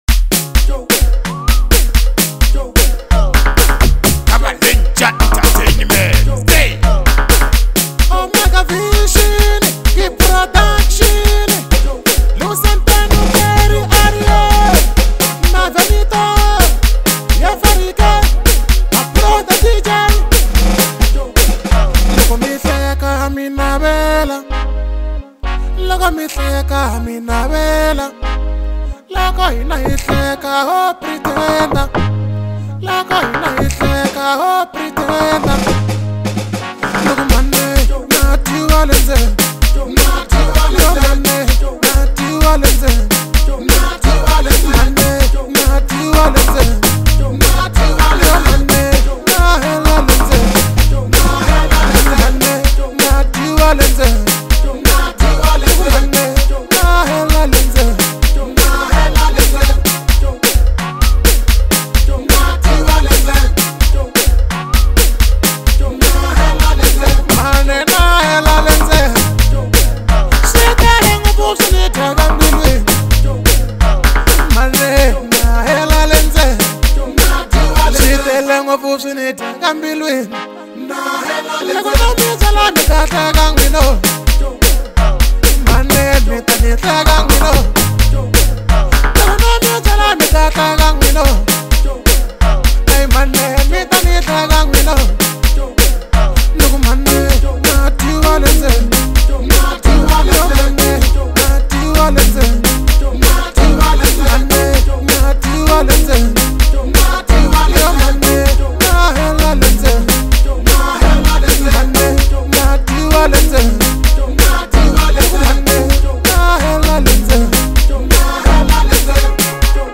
powerful and emotionally charged track